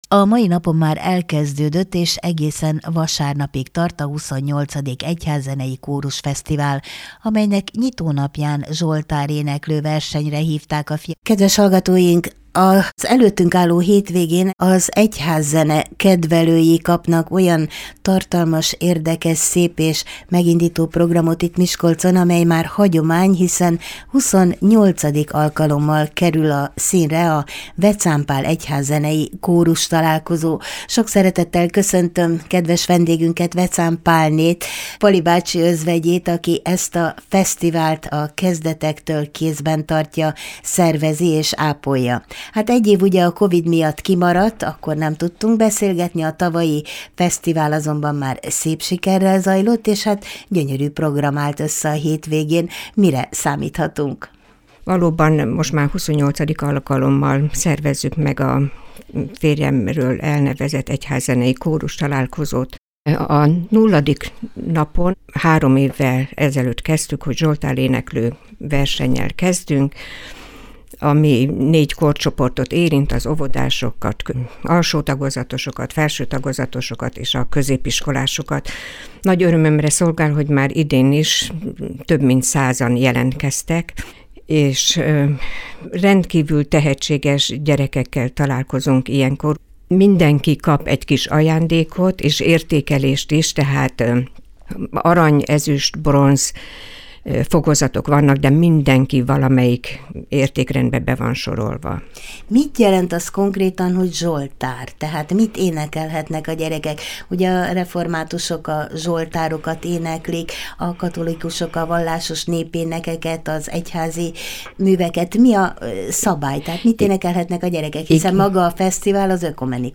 15-3_egyhazzenei_korusfesztzene.mp3